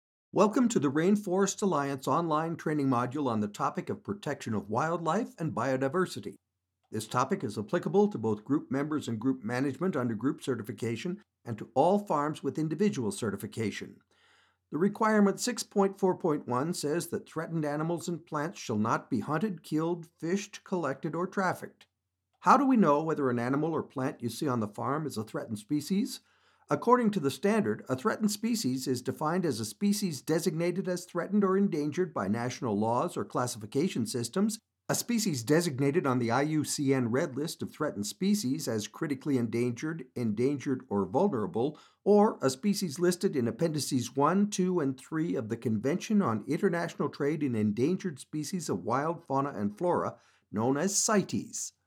A signature voice - mature, warm, and engaging
RAINFOREST ALLIANCE - eLearning course - Sustainable Agriculture
Middle Aged